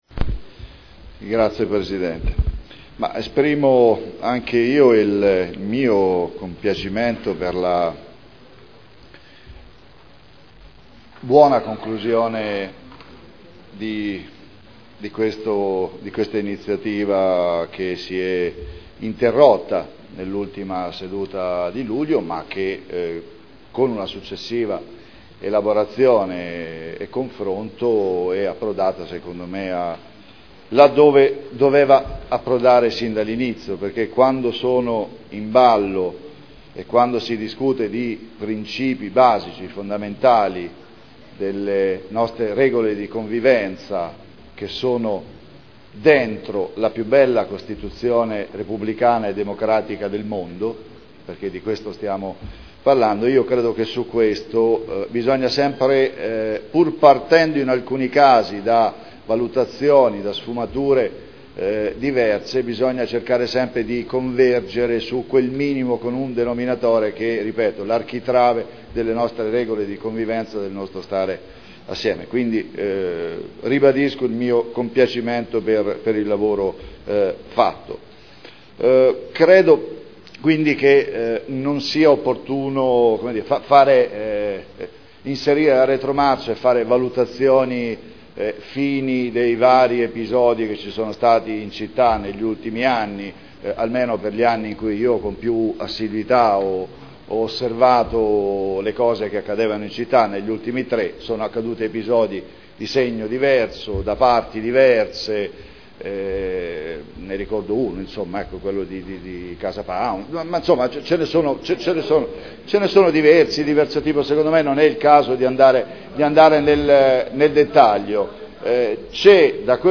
Paolo Trande — Sito Audio Consiglio Comunale